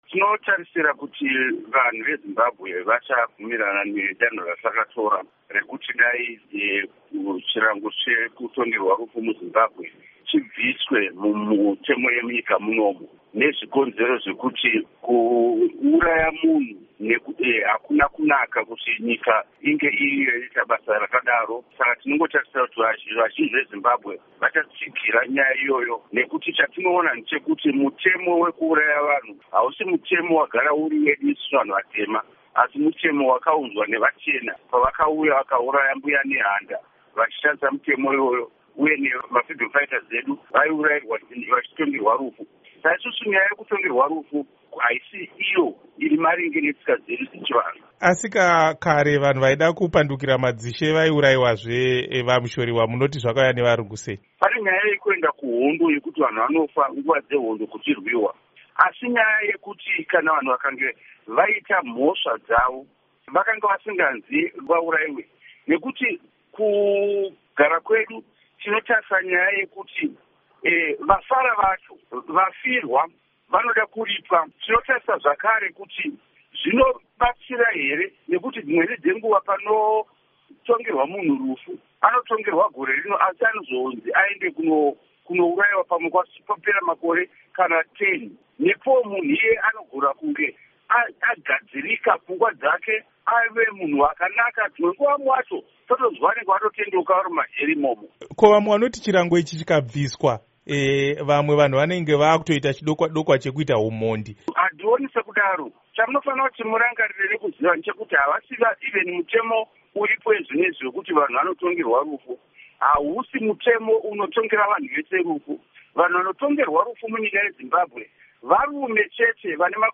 Hurukuro naVaEdwin Mushoriwa